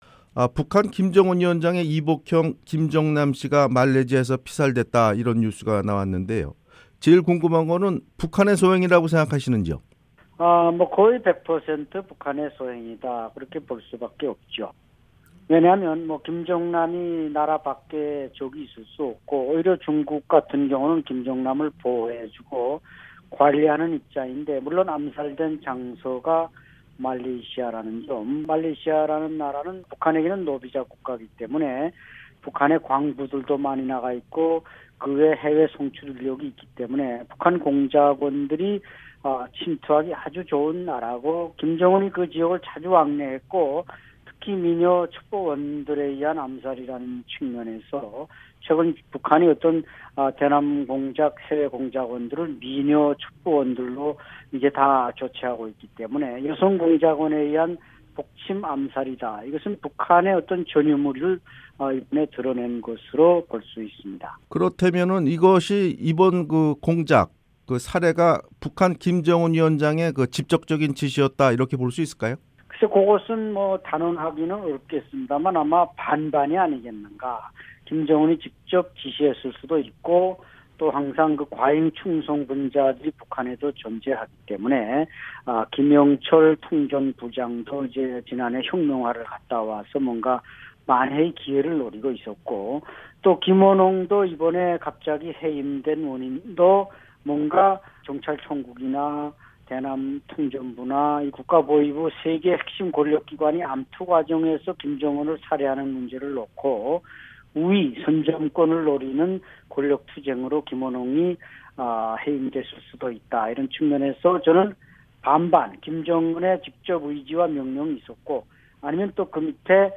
[인터뷰 오디오: